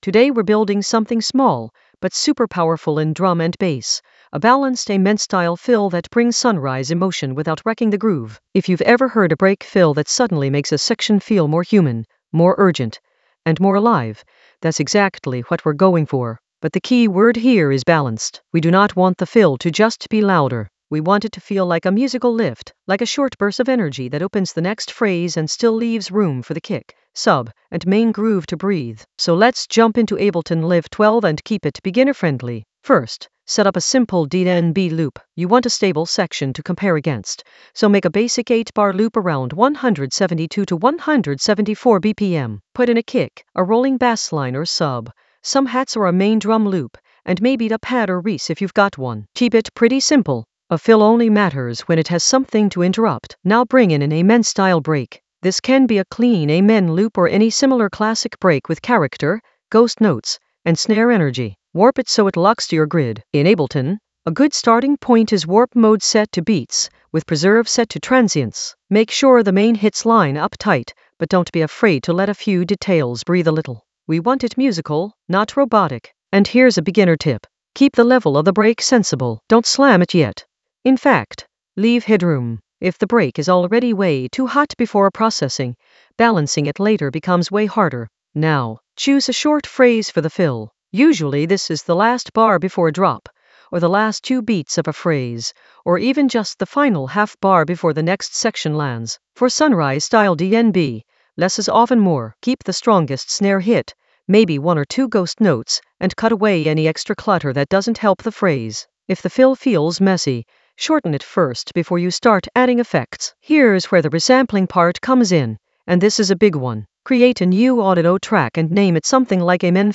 An AI-generated beginner Ableton lesson focused on Balance an Amen-style fill for sunrise set emotion in Ableton Live 12 in the Resampling area of drum and bass production.
Narrated lesson audio
The voice track includes the tutorial plus extra teacher commentary.